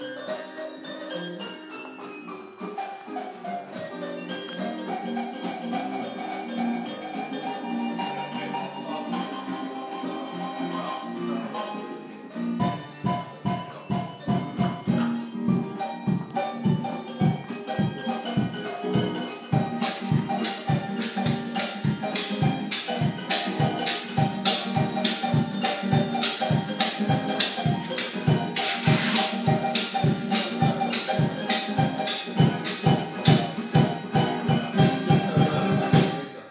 Playing in the studio